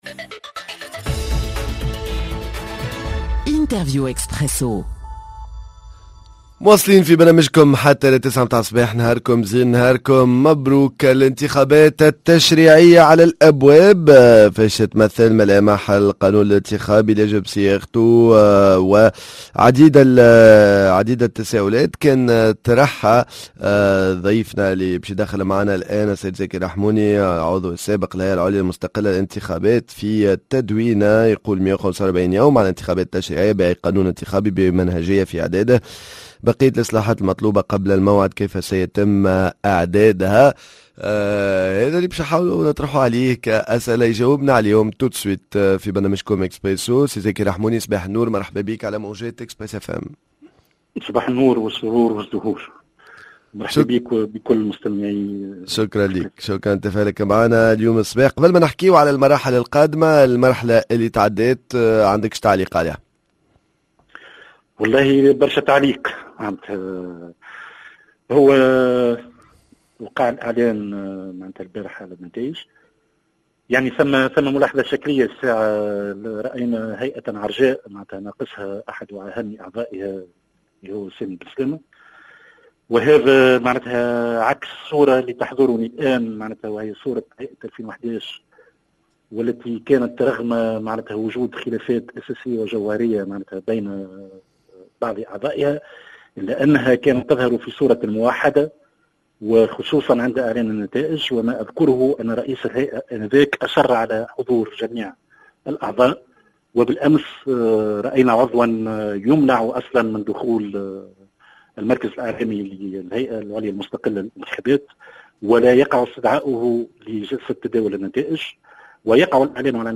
الانتخابات التشريعية على الأبواب: فاش تتمثل ملامح القانون الانتخابي الي يجب صياغته، معنا عبر الهاتف العضو السابق للهيئة العليا المستقلة للانتخابات زاكي الرحموني